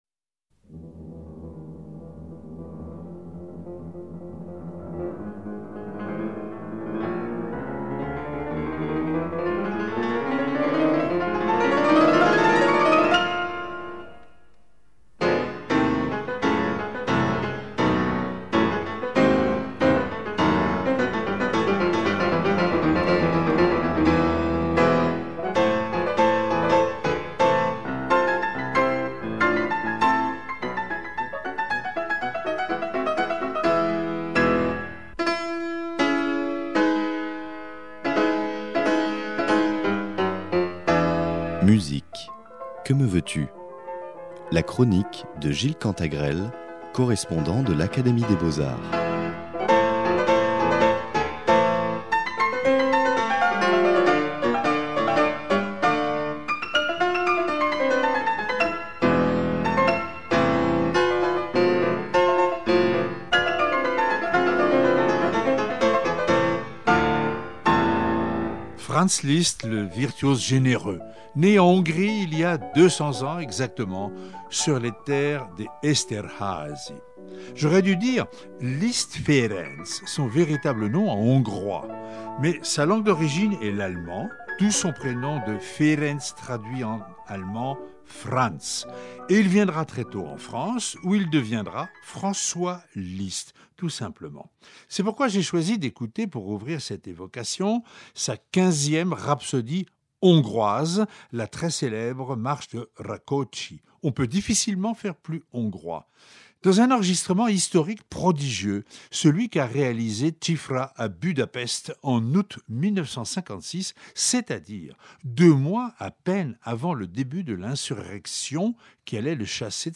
Il y a la virtuosité stupéfiante du pianiste, bien sûr, ses doigts d’acier, et cette manière inimitable de paraître jouer plus vite que son piano.